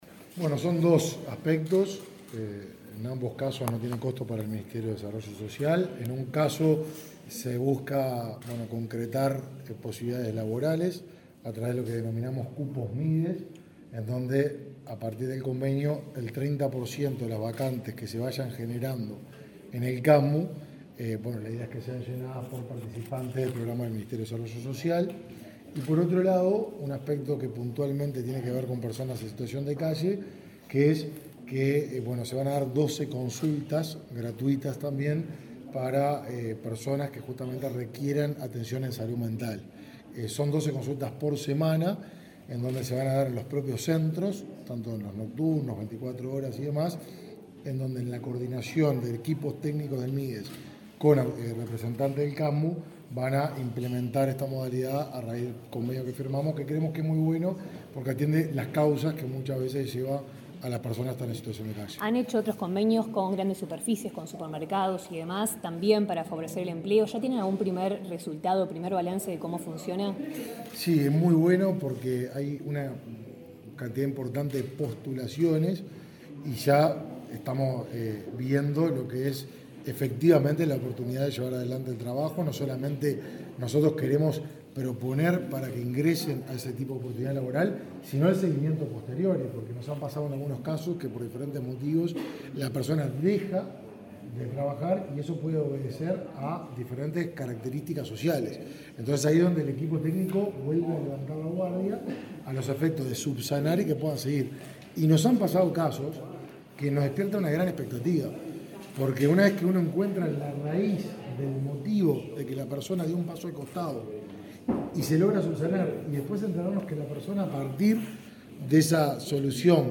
Declaraciones a la prensa del ministro de Desarrollo Social, Martín Lema